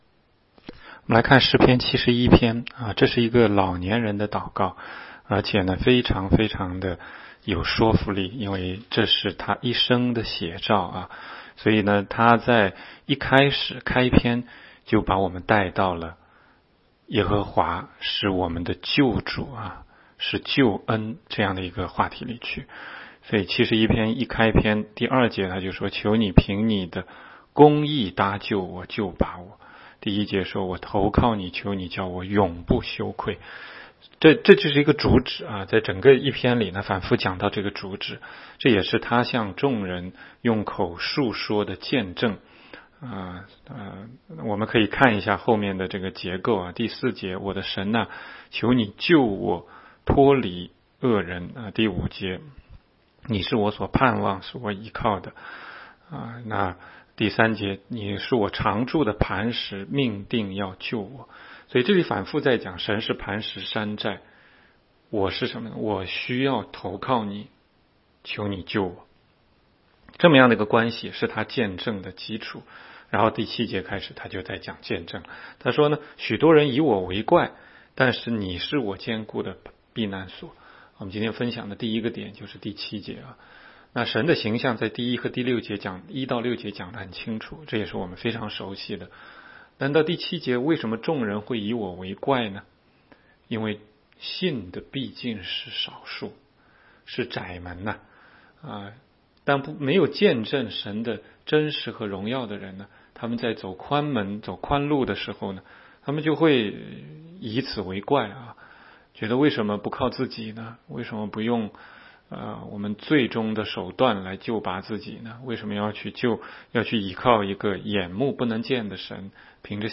16街讲道录音 - 每日读经-《诗篇》71章